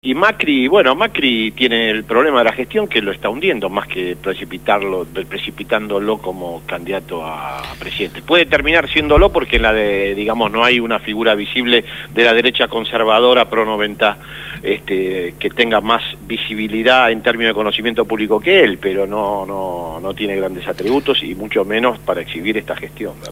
Verborrágico, locuaz.